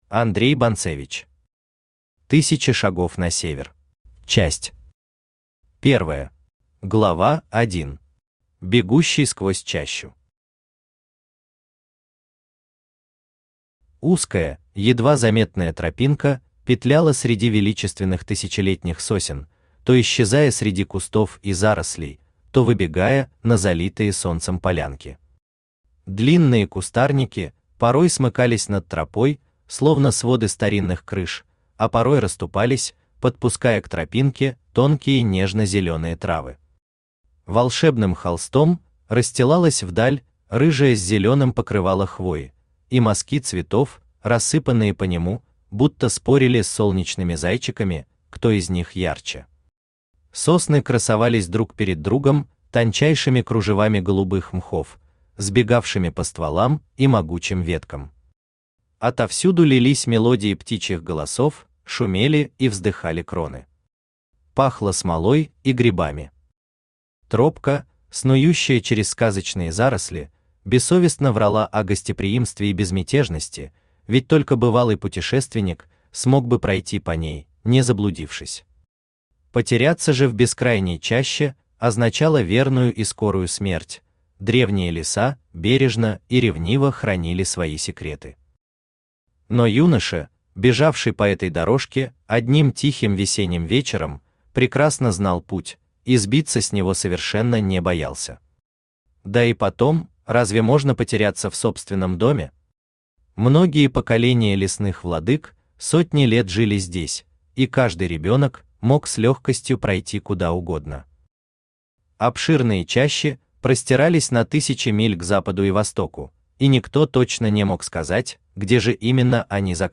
Аудиокнига Тысяча шагов на север | Библиотека аудиокниг
Aудиокнига Тысяча шагов на север Автор Андрей Бонцевич Читает аудиокнигу Авточтец ЛитРес.